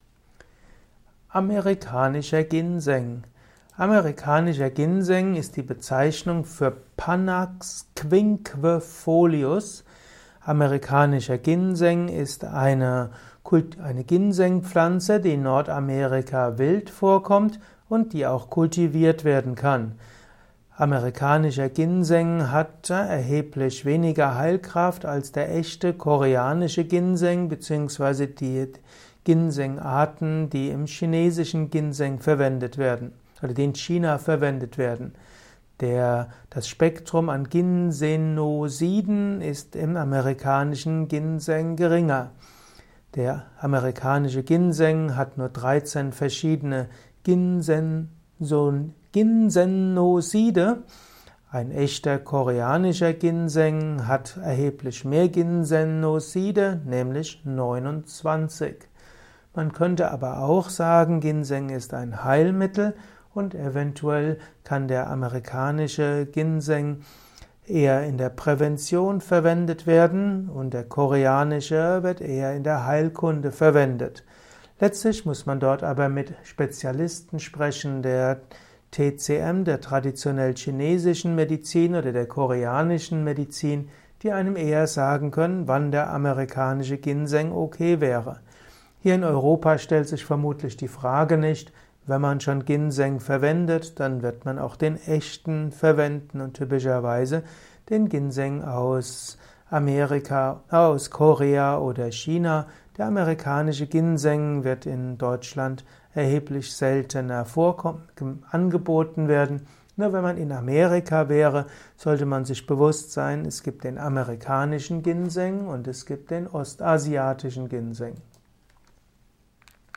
Simple und komplexe Infos zum Thema Amerikanischer Ginseng in diesem Kurzvortrag